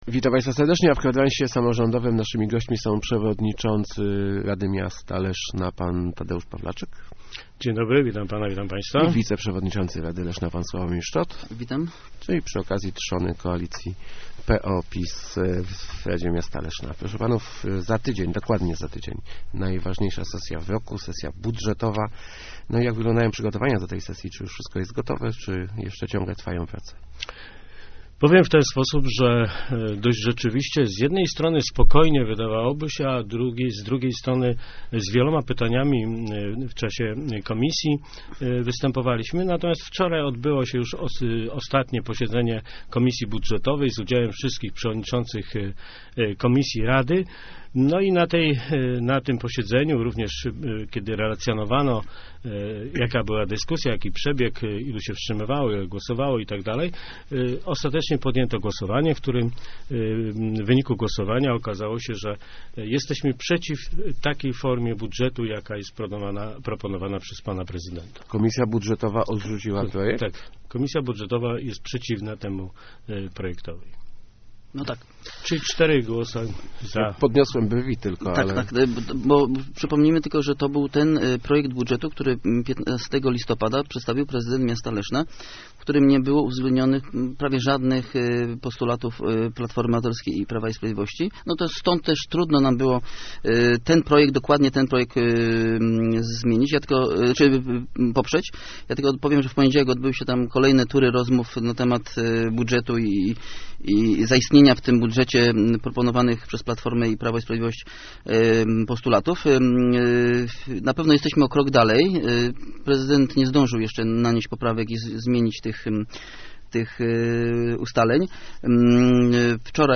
Gośćmi Kwadransa są przewodniczący RML Tadeusz Pawlaczyk i wiceprzewodniczący Sławomir Szczot.